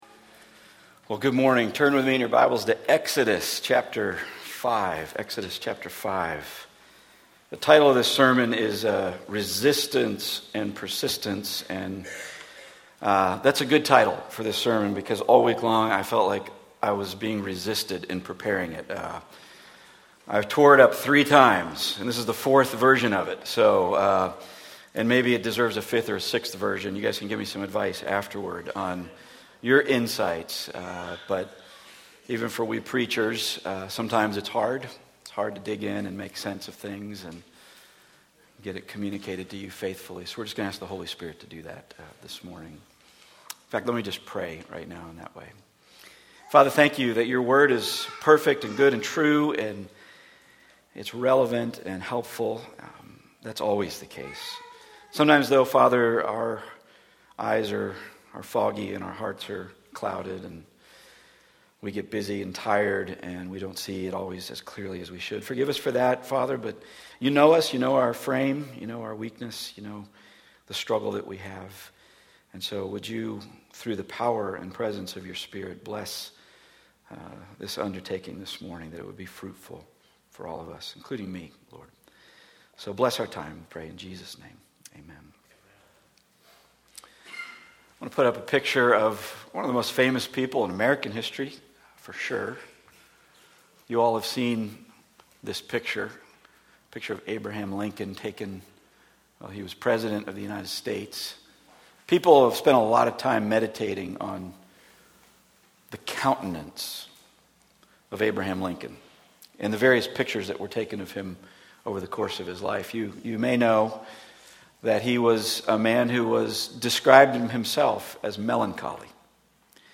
Passage: Exodus 5:1-6:27 Service Type: Weekly Sunday